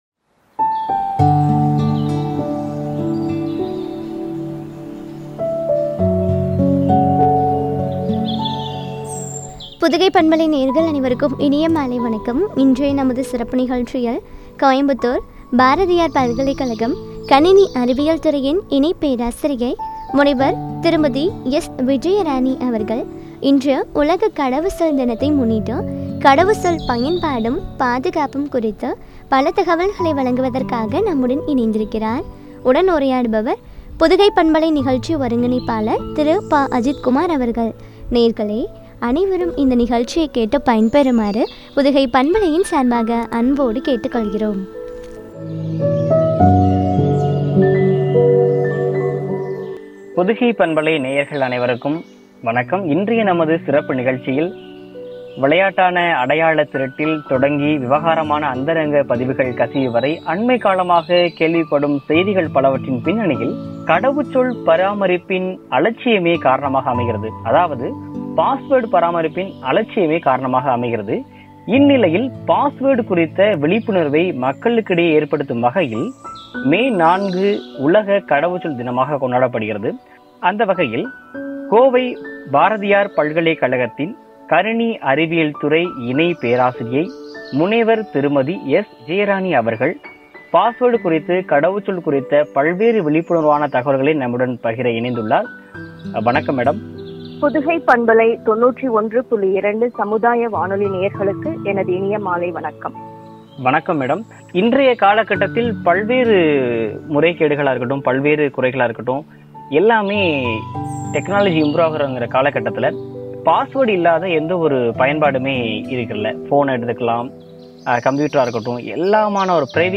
பாதுகாப்பும் பற்றிய உரையாடல்.